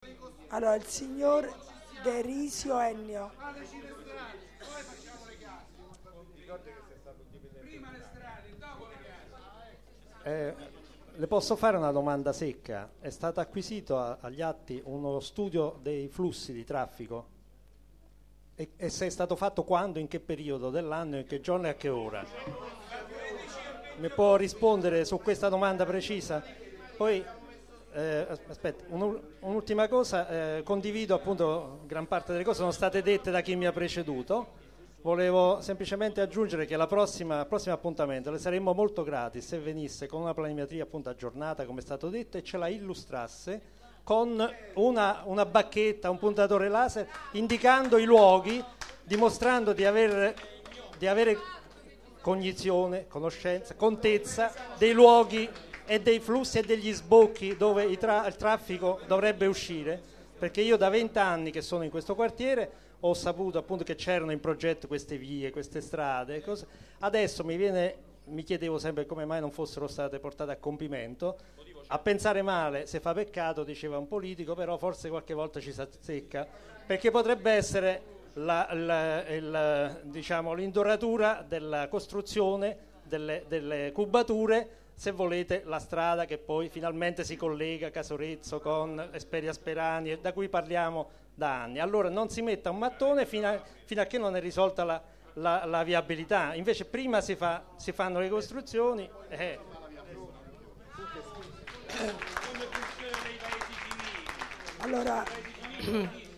Registrazione integrale dell'incontro svoltosi il 5 marzo 2013 la Sala Teatro della ex Scuola primaria "Elsa Morante" in Via Casal del Marmo, 212.
cittadino.